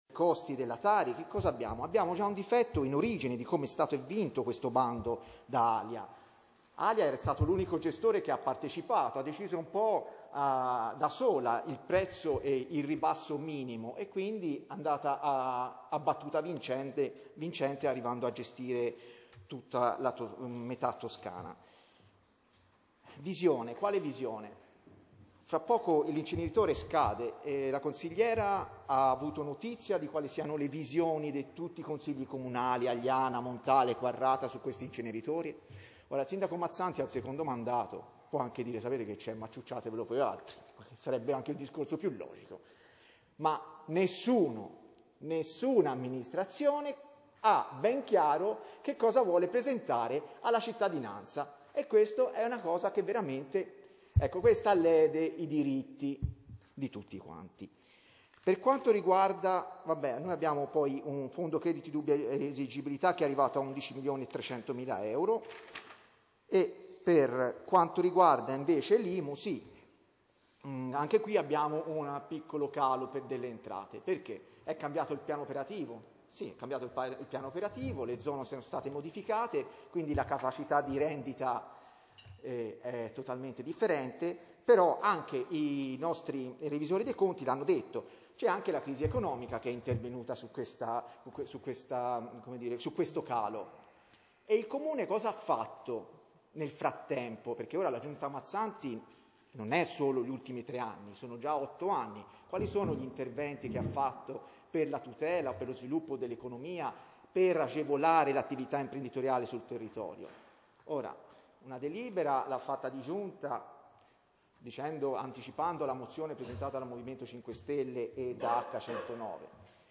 Seduta ordinaria del Consiglio Comunale
Convocazione seduta Consiglio Comunale giorno 29/05/2020 alle ore 20,45.